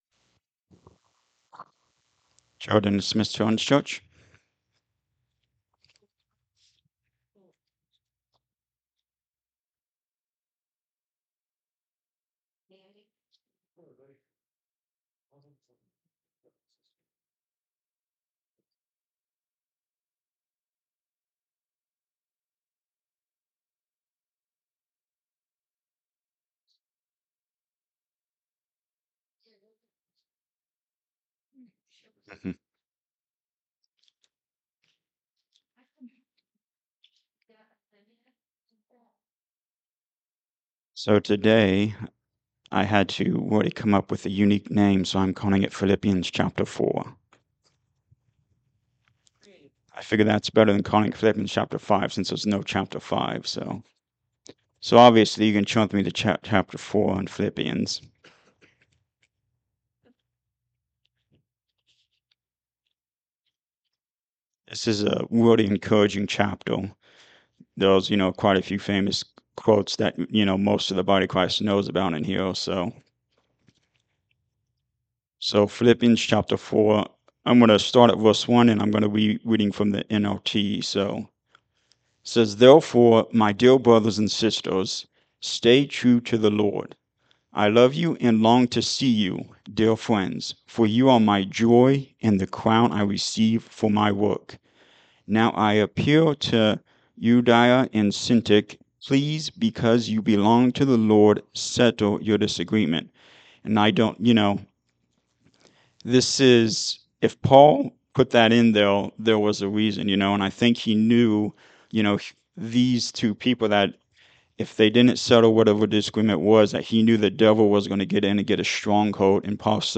Sunday-Sermon-for-February-8-2026.mp3